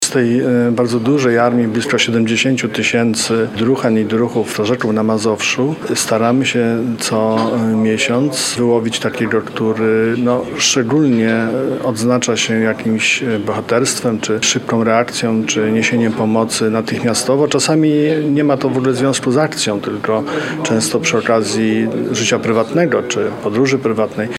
– Nagrodę otrzymują prawdziwi bohaterowie – mówi marszałek Adam Struzik.